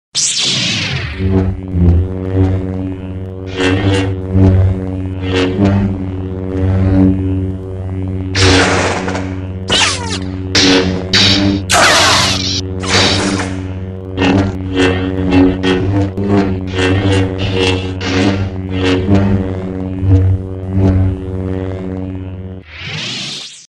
На этой странице собраны реалистичные звуки светового меча из вселенной Star Wars.
Звуки лазерного меча